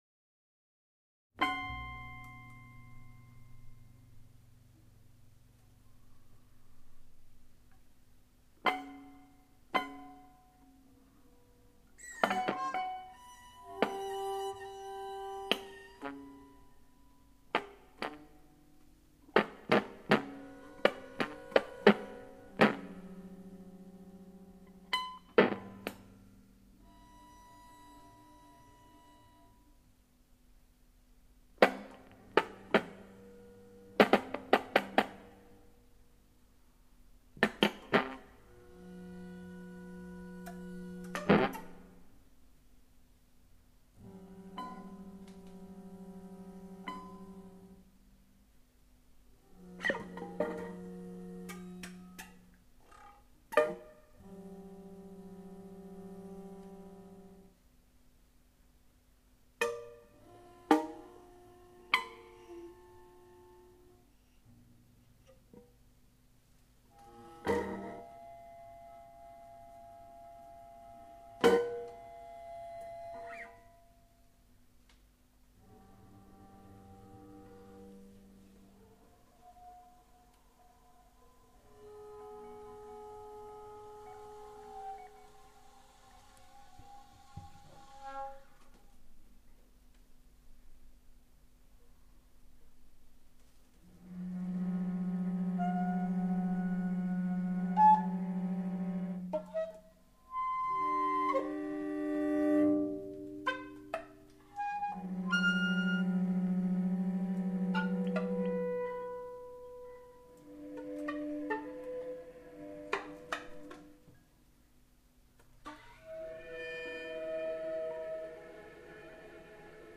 trio
Flute / Sax
Guitar
Cello/ Bandoneon.